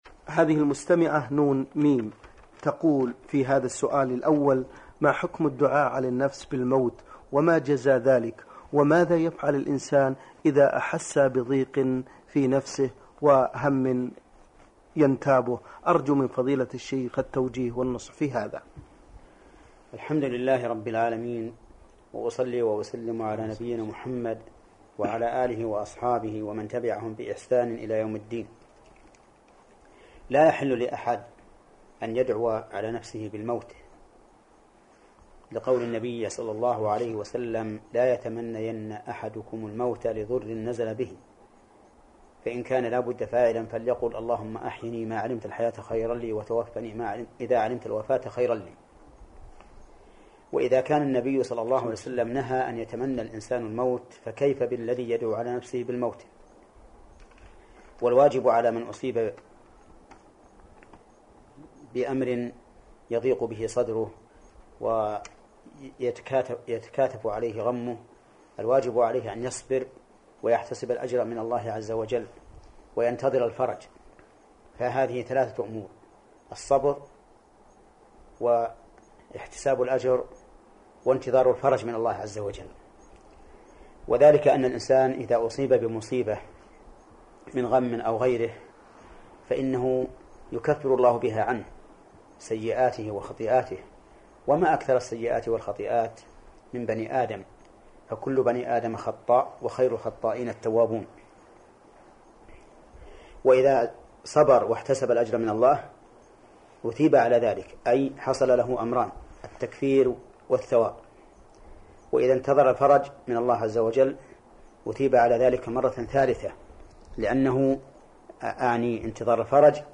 لفضيلة الشيخ العلامة محمد بن صالح العثيمين رحمه الله تعالى